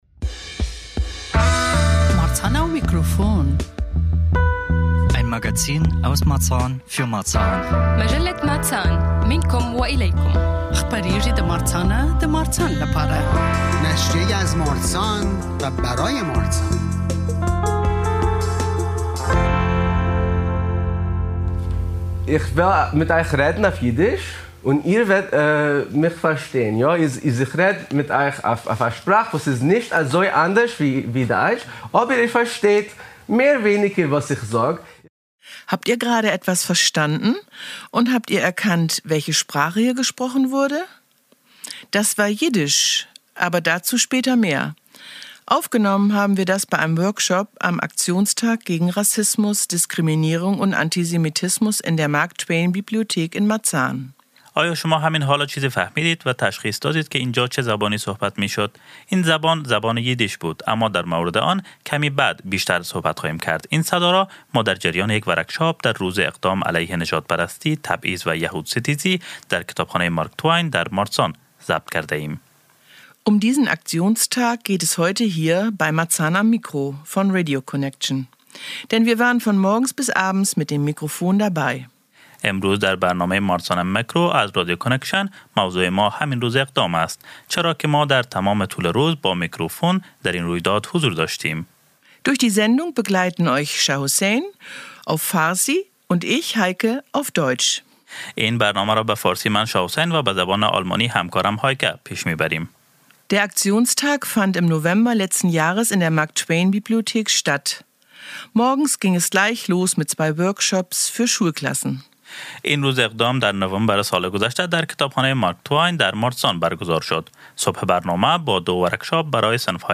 Am Desinfopoint im Foyer konnte man sein Wissen über Medien und Falschinformationen spielerisch testen. Abends las dann der Schauspieler Peter Jordan aus seinem Buch: Kein schöner Land: Papas Krieg, meine Nazis und die deutsche Kultur. Wir waren den ganzen Tag mit dem Mikrofon dabei und haben mit einigen Akteuren und Teinehmer*innen gesprochen.